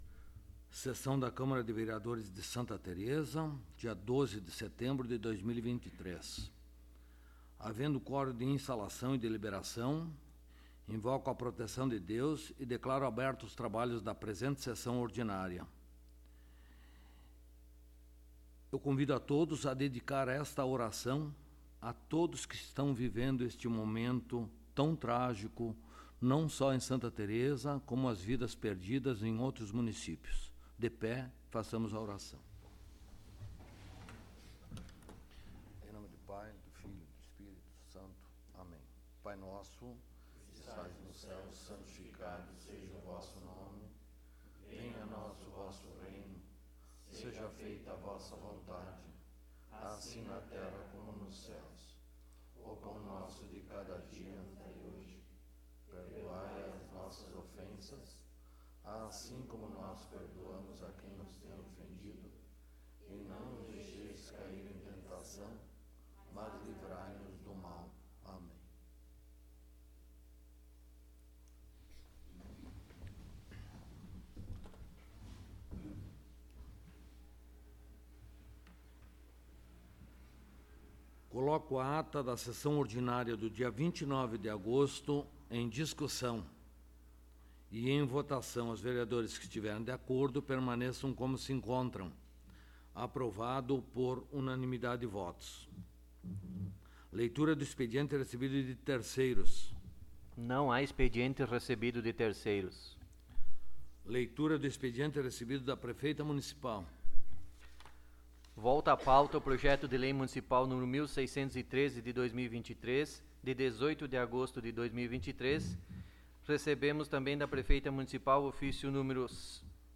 15ª Sessão Ordinária de 2023
Local: Câmara Municipal de Vereadores de Santa Tereza